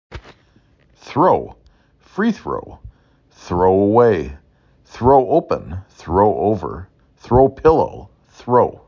3 Phonemes
T r O
thr ow